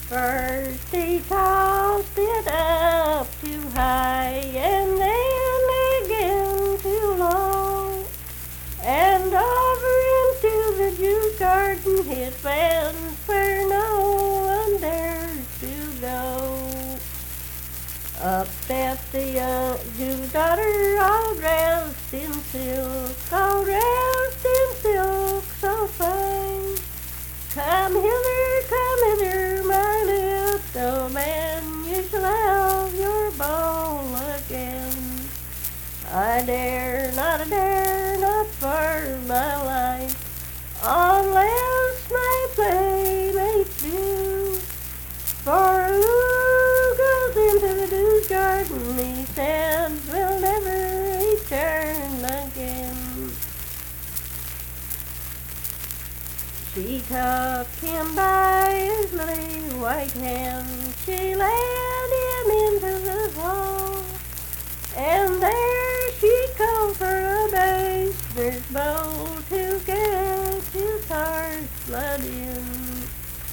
Unaccompanied vocal music performance
Strange Creek, Braxton County, WV
Verse-refrain 4(4).
Voice (sung)